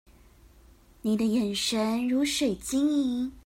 Тайваньский 430